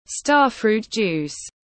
Nước ép khế tiếng anh gọi là starfruit juice, phiên âm tiếng anh đọc là /’stɑ:r.fru:t ˌdʒuːs/
Starfruit juice /’stɑ:r.fru:t ˌdʒuːs/